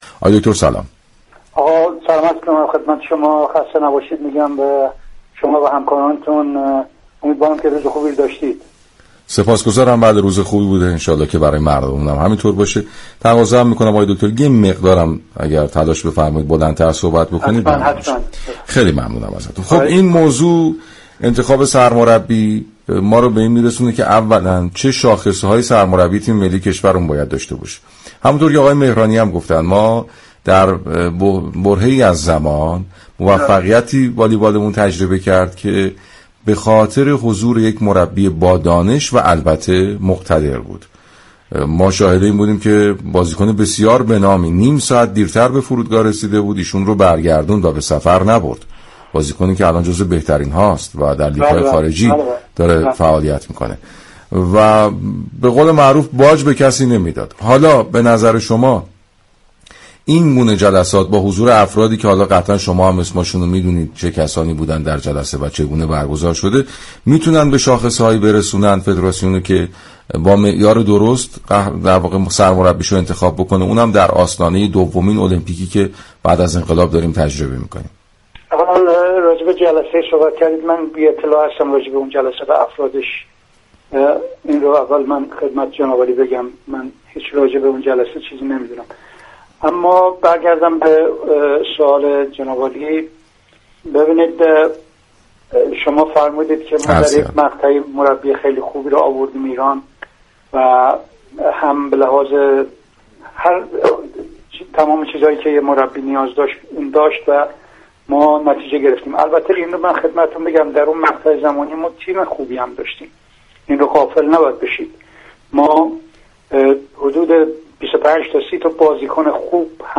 گفتگوی كامل